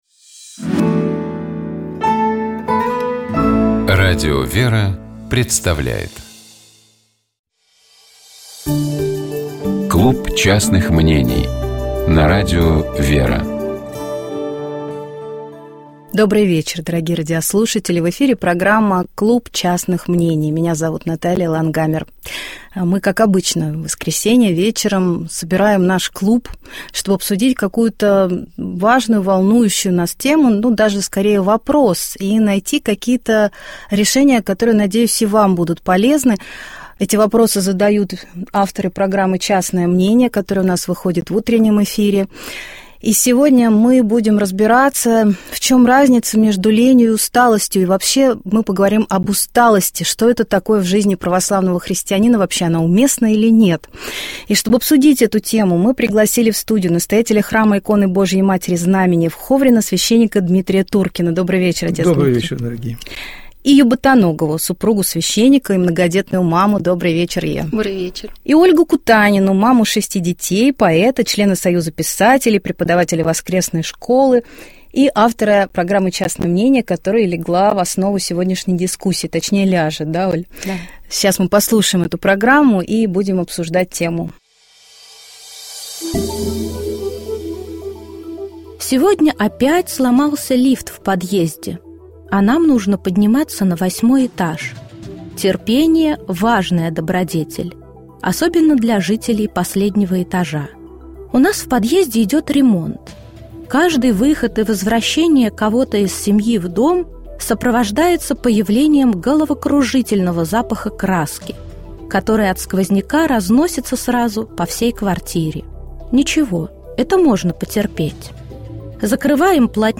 Программа «Светлый вечер» — это душевная беседа ведущих и гостей в студии Радио ВЕРА.